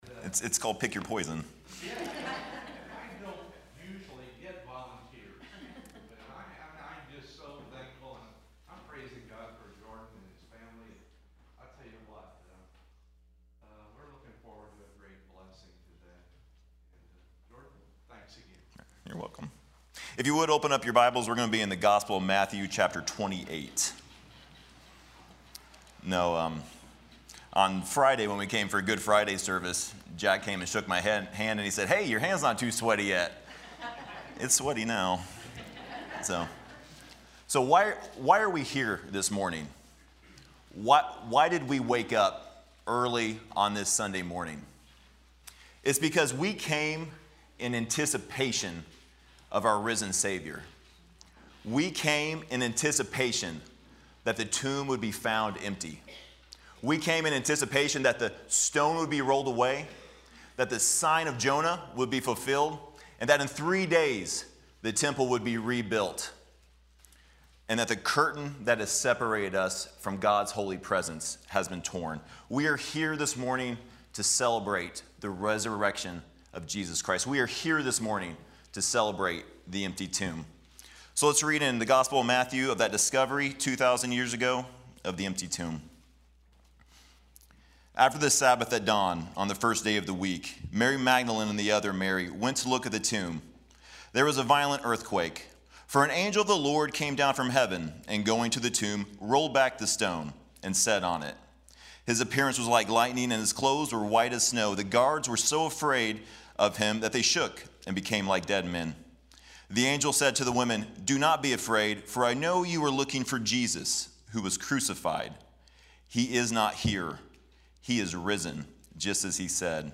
03/31/2024 Sunrise Service